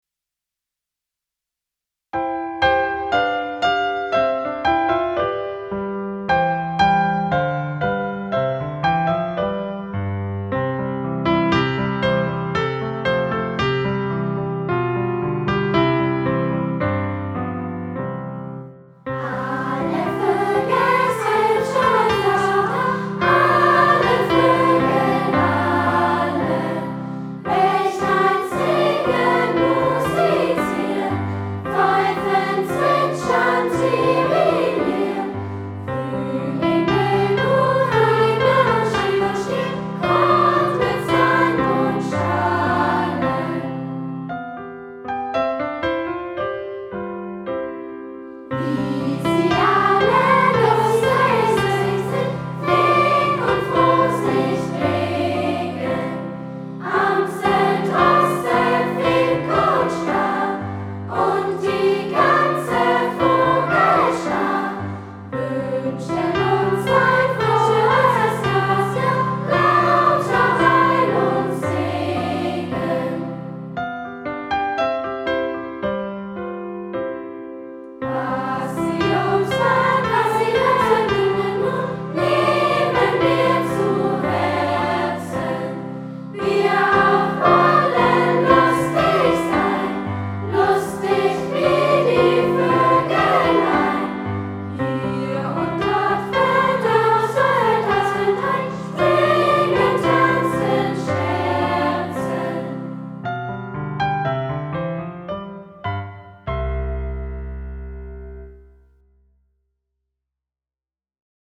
Alle_Voegel_sind_schon_da_Cantemus_Kinderchor_FEU.mp3